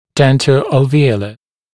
[ˌdentə(u)əlvɪ’əulə][ˌдэнто(у)элви’оулэ]дентоальвеолярный, зубоальвеолярный